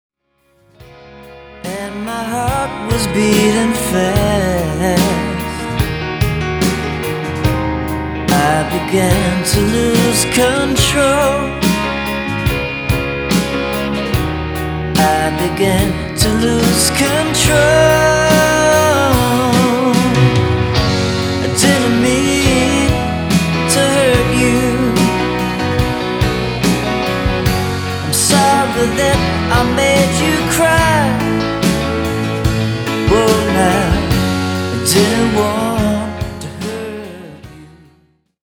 The theme for this release is Red Hot Guitar
is an unexpected and soulful high point.